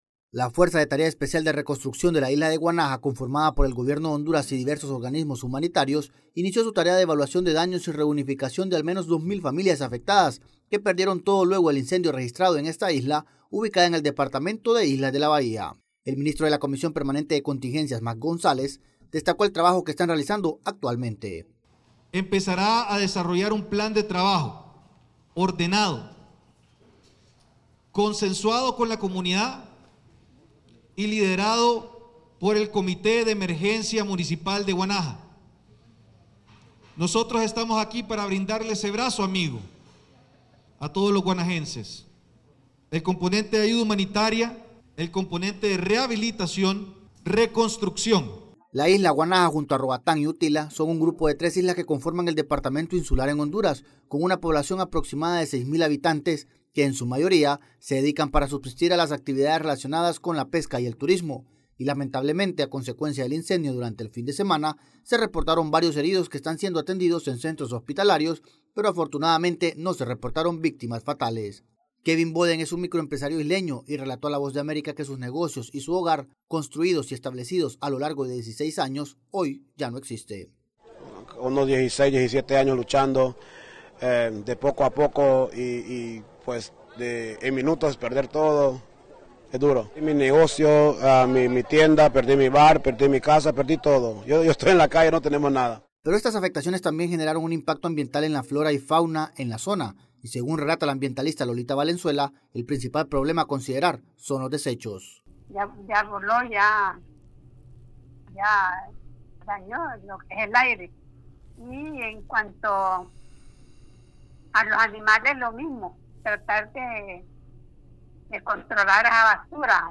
AudioNoticias
En Honduras se han iniciado los trabajos de limpieza y reconstrucción de la Isla Guanaja luego del incendio que consumió más de un centenar de viviendas y negocios turísticos. Desde Tegucigalpa informa el corresponsal de la Voz de América